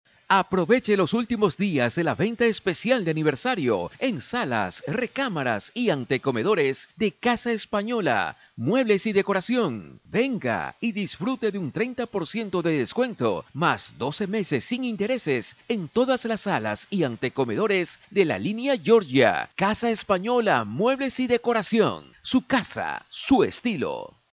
spanisch Südamerika
Sprechprobe: Industrie (Muttersprache):
corto promocinal_0.mp3